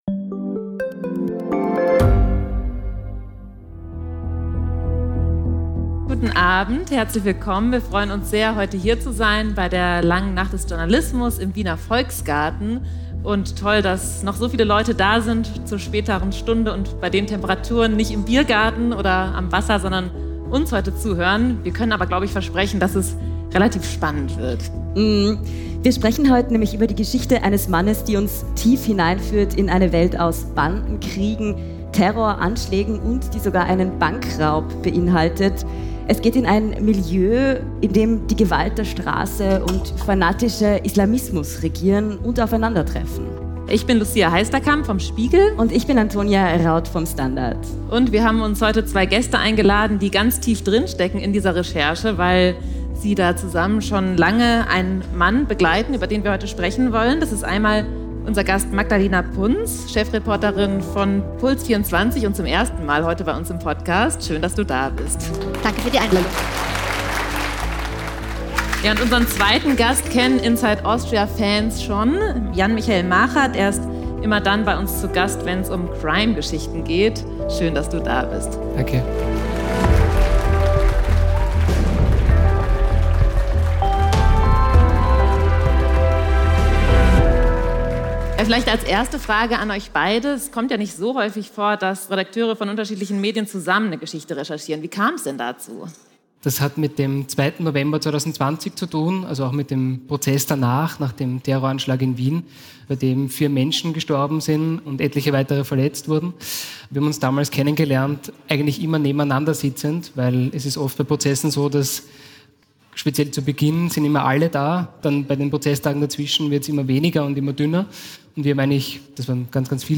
Darüber sprechen wir in dieser Live-Folge von Inside Austria.